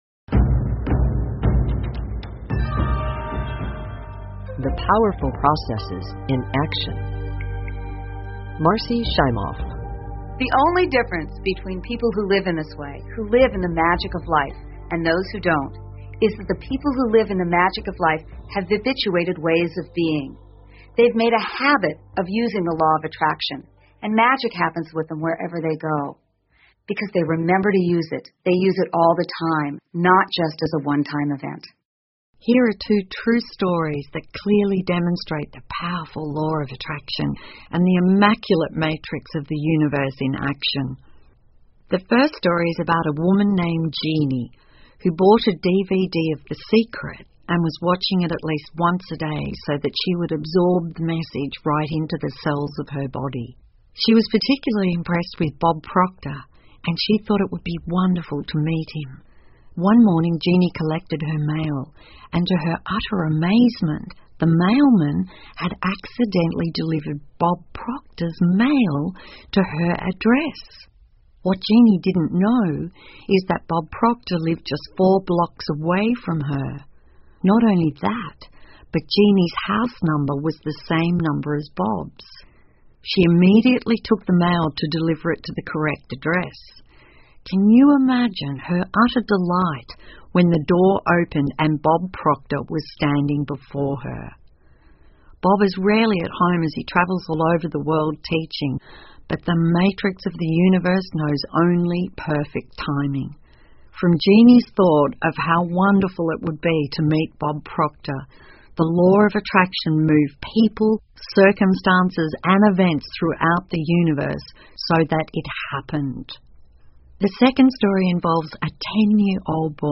有声畅销书-秘密 2-23 The Powerful Processes in Actio 听力文件下载—在线英语听力室